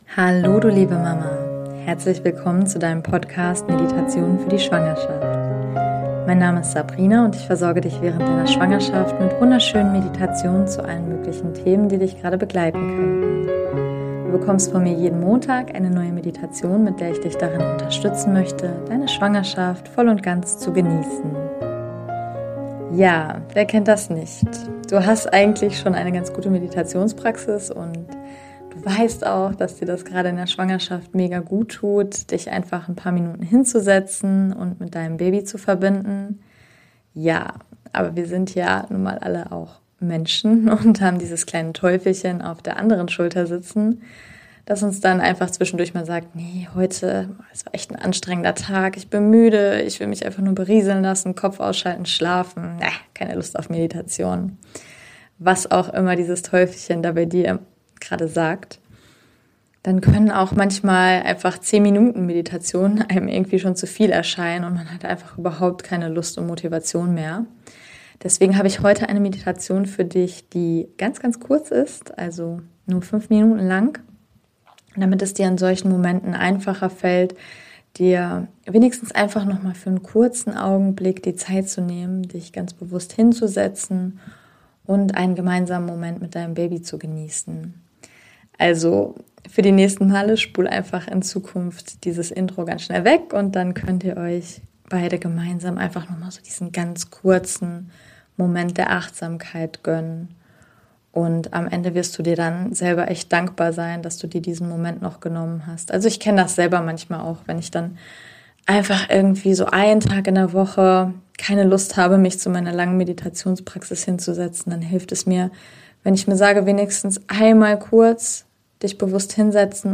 #015 - 5 Minuten Meditation der Präsenz mit deinem Baby